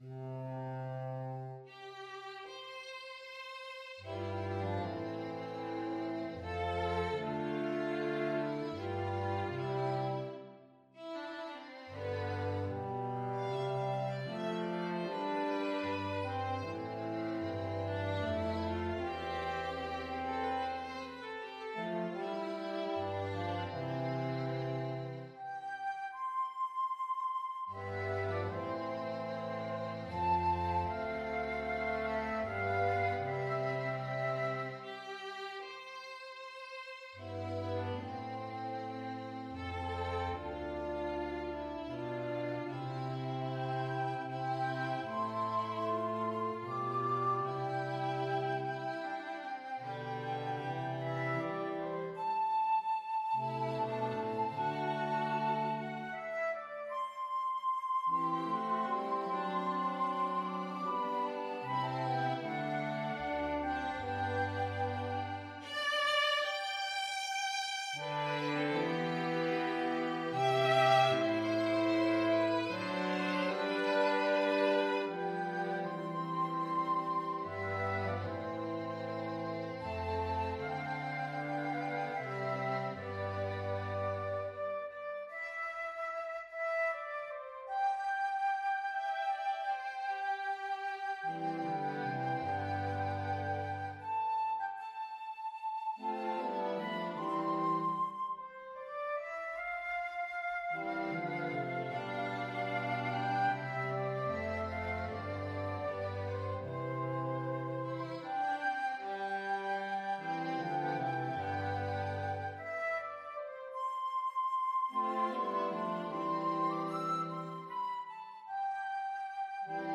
Flute
Violin
French Horn
Baritone Saxophone
C major (Sounding Pitch) (View more C major Music for Flexible Mixed Ensemble - 4 Players )
3/4 (View more 3/4 Music)
Larghetto (=76)
Scottish